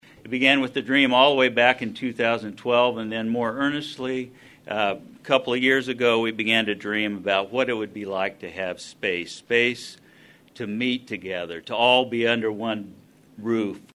Manhattan celebrated Stormont Vail Health’s new campus with a ribbon cutting Friday